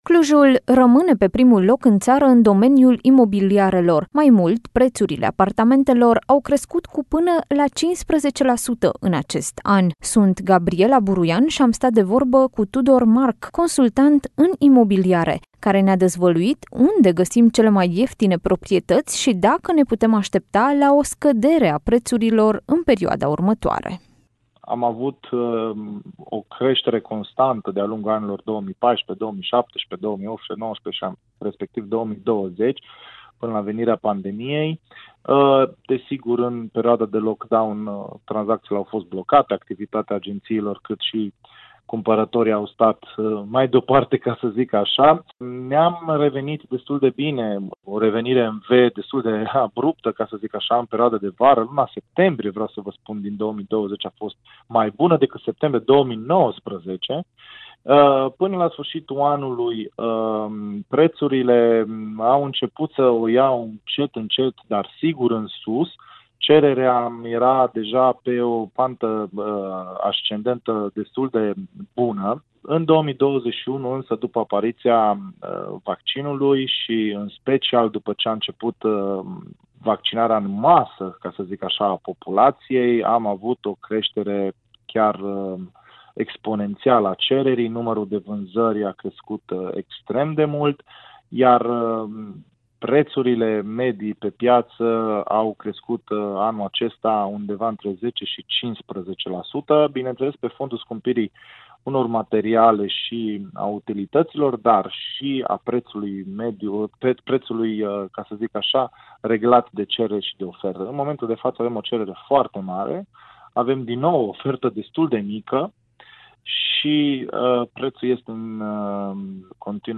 într-o conversație